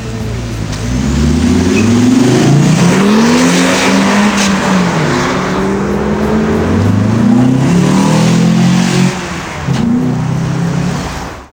Le bruit du Coupé turbo 16v